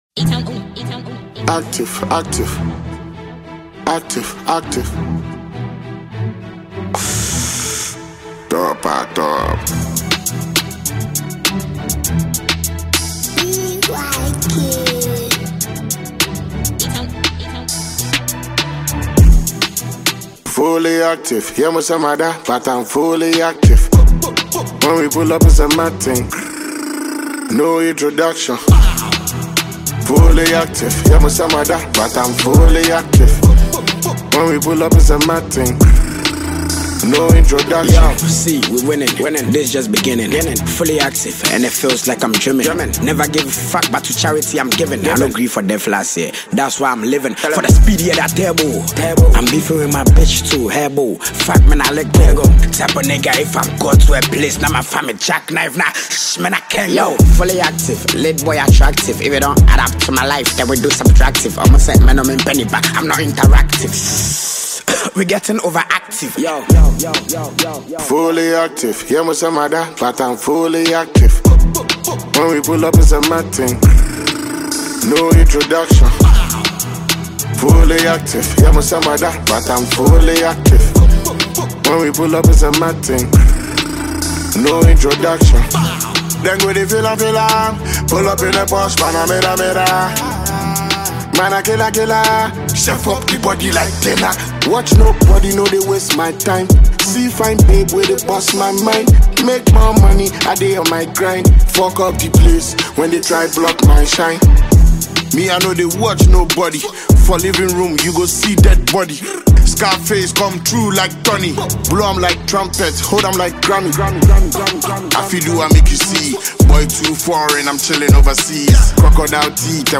GHANA MUSIC
Heavyweight Ghanaian rapper